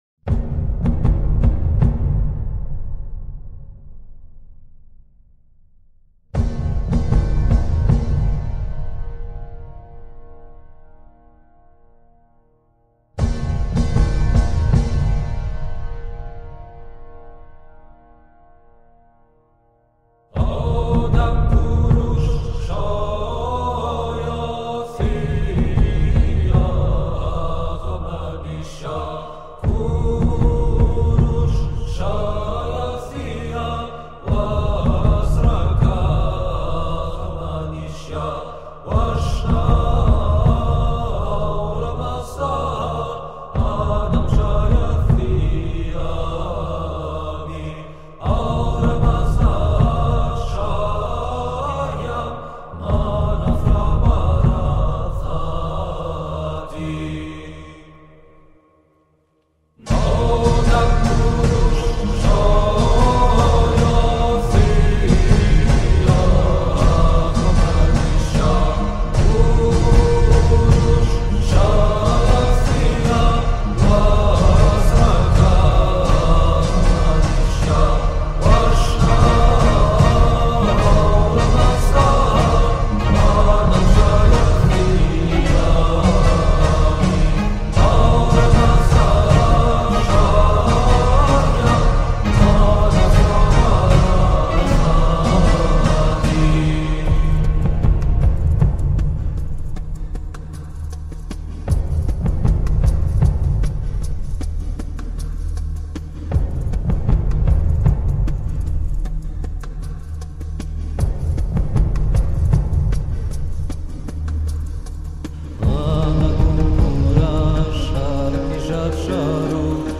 música épica iraniana